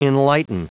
Prononciation du mot enlighten en anglais (fichier audio)
Prononciation du mot : enlighten